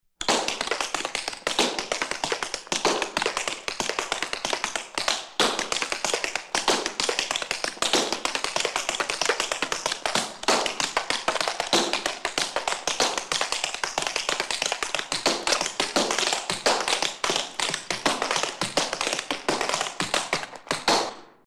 Звуки танца
Звук чечётки танцора на полу